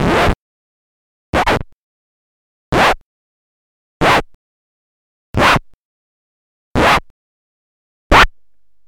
Vinyl Needle Skip
comic crash ep funny humour lp needle record sound effect free sound royalty free Funny